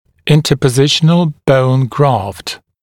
[ˌɪntəpə’zɪʃənəl bəun grɑːft][ˌинтэпэ’зишэнэл боун гра:фт]подсадка перемещенной кости